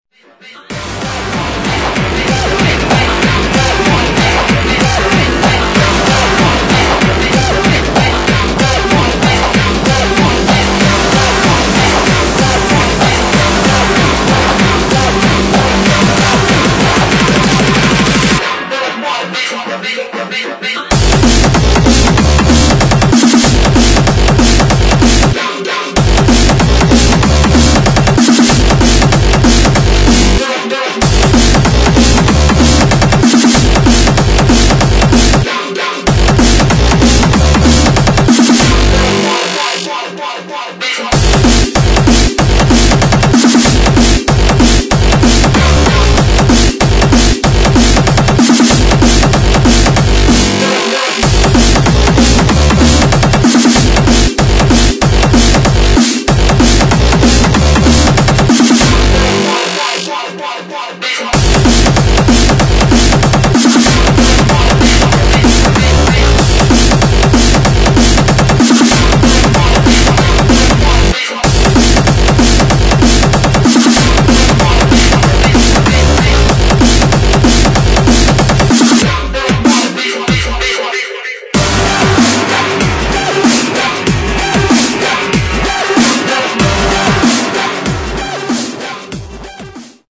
[ HARDCORE / DRUM'N'BASS ]